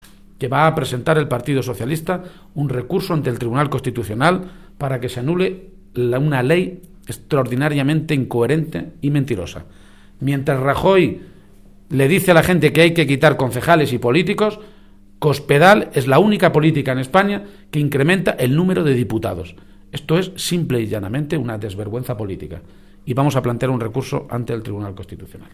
García-Page inauguró en la capital conquense una Jornada de Política Municipal organizada por el PSOE de Cuenca y a la que asistieron más de 200 alcaldes y concejales de esta provincia